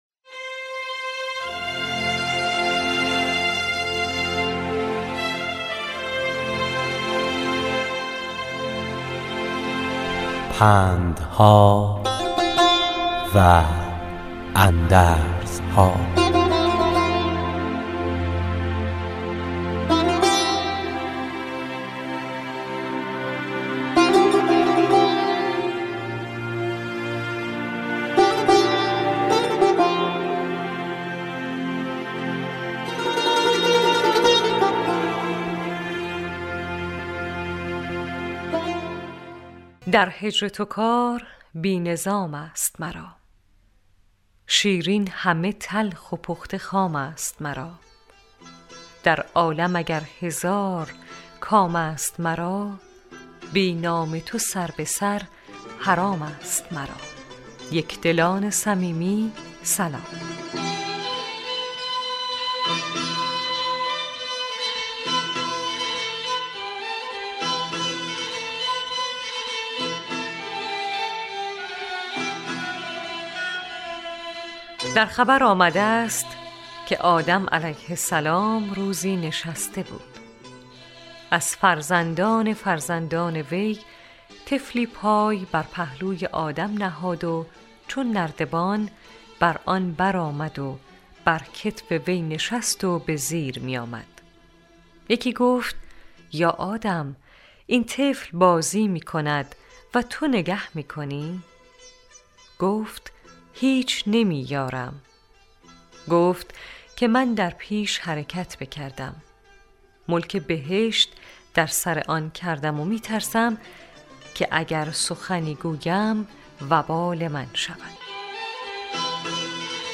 راوی برای شنوندگان عزیز صدای خراسان، حکایت های پندآموزی را روایت می کند